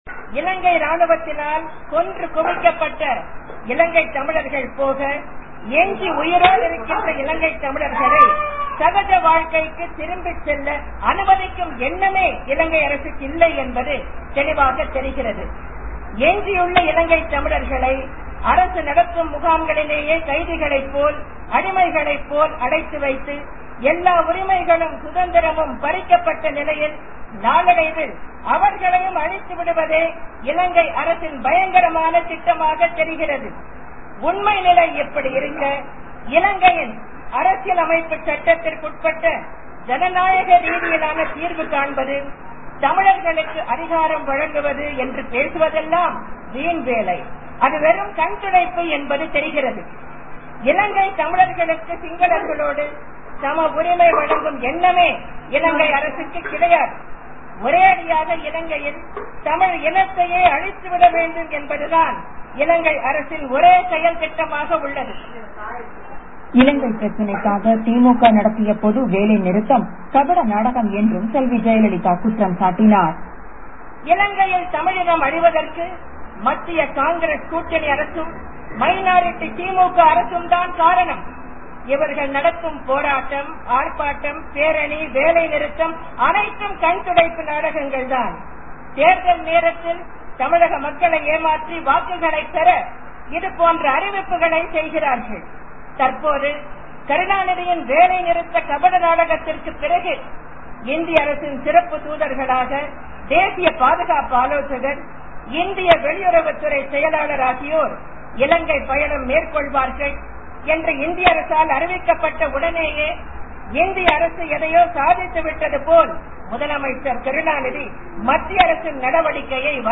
A separate Tamil Eelam is the only solution that will permanently put an end to the problems of the Tamil people in the island of Sri Lanka, said Tamil Nadu former Chief Minister and principal Leader of the Opposition, Ms. Jayalalitha at a election rally in Salem city Saturday. In a powerful, moving speech, she resolved to fight to attain independent Eelam.
Voice: Extracts of Ms. Jayalalitha's address
jeyalalitha_speech.mp3